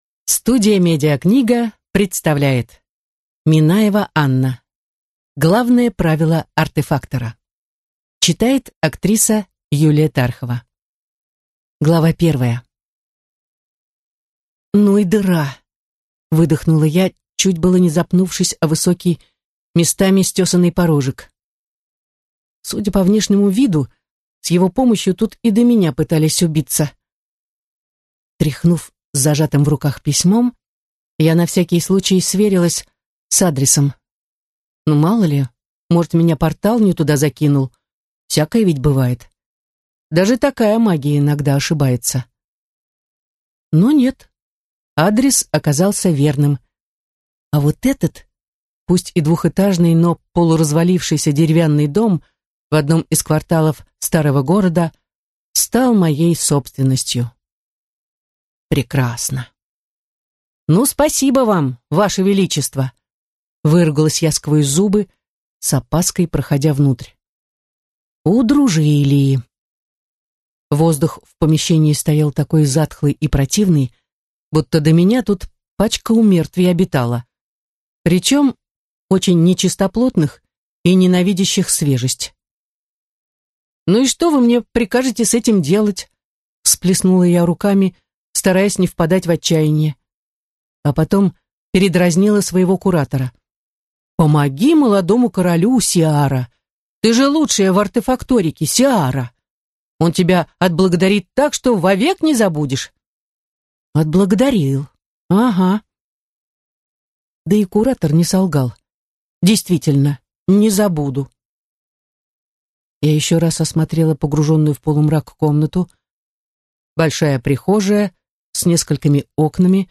Аудиокнига Главное правило артефактора | Библиотека аудиокниг